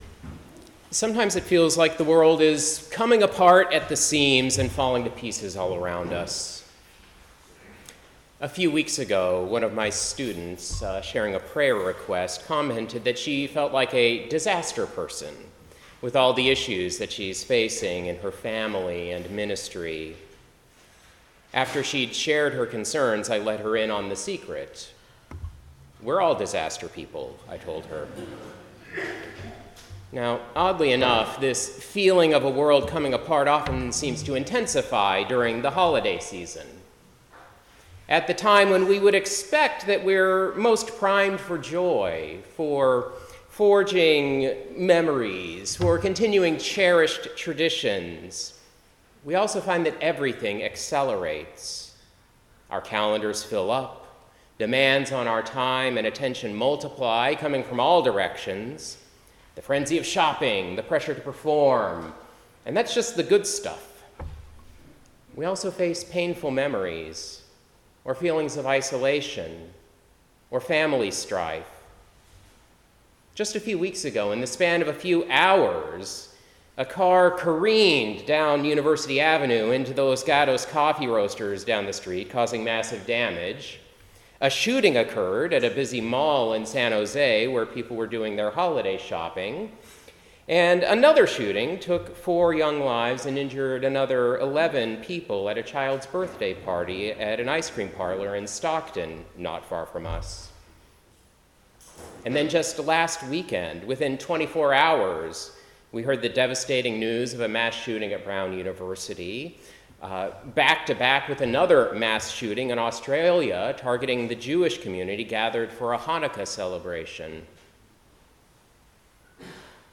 Preacher
10:00 am Service